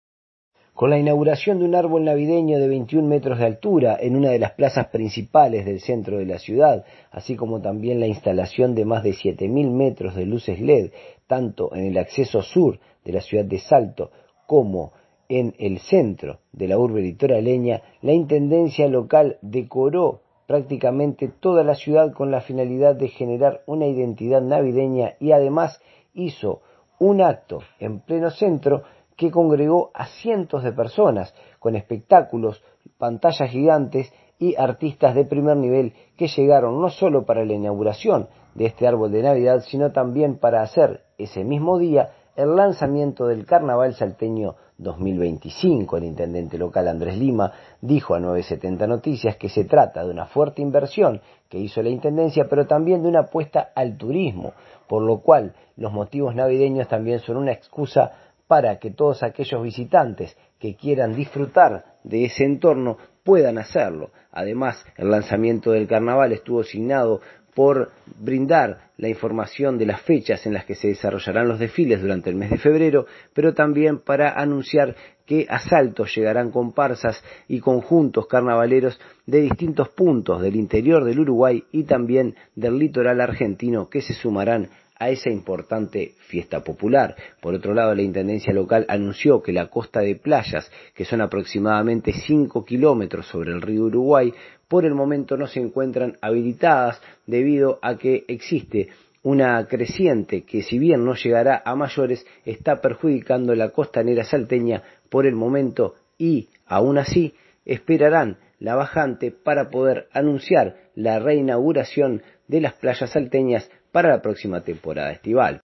El informe del corresponsal para 970 Noticias